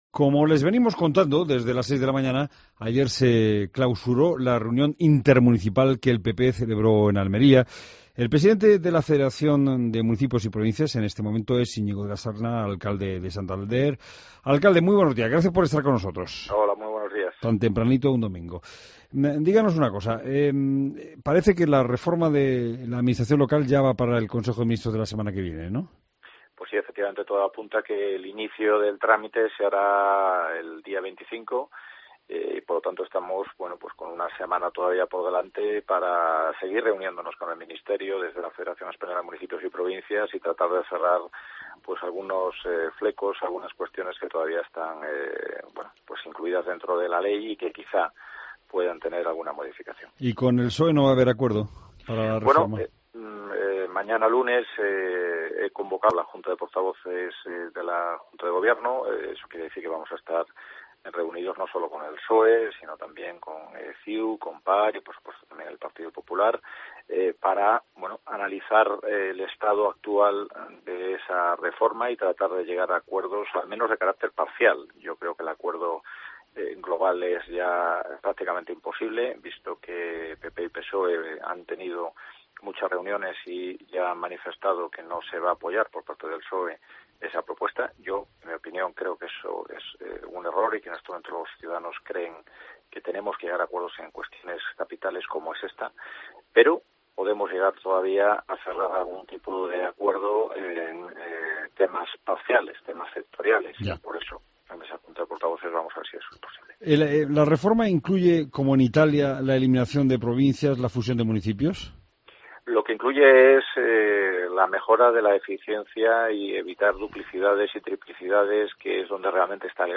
AUDIO: Entrevista con Íñigo de la Serna, alcalde de Santander y presidente de la FEMP